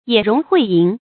冶容诲淫 yě róng huì yín
冶容诲淫发音